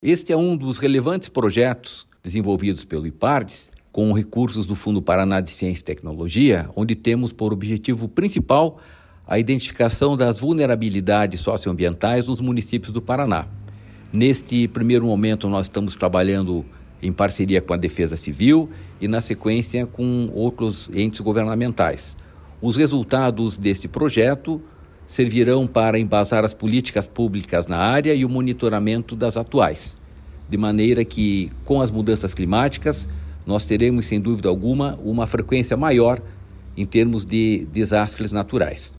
Sonora do presidente do Ipardes, Jorge Callado, sobre a parceria com a Defesa Civil para criar indicadores de vulnerabilidade socioambiental no Paraná